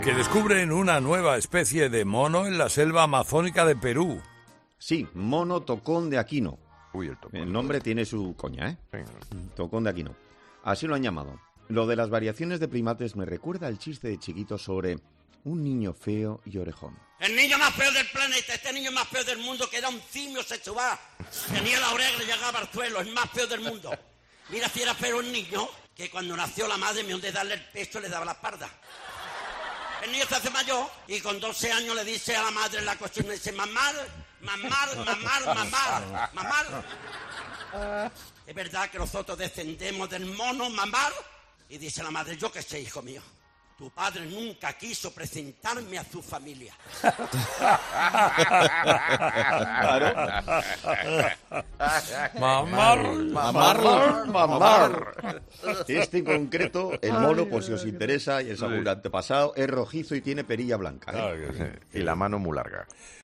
Escucha el chiste de Chiquito de la Calzada y la imitación de Herrera en el siguiente audio:
El chiste de Chiquito que ha desatado las risas en 'Herrera en COPE'
Tras escuchar el chiste en cuestión, el equipo de 'Herrera en COPE' no ha podido ocultar las risas hasta tal punto que el mismo Carlos Herrera se ha arrancado con otros colaboradores a imitar la característica dicción del genio de la Calzada.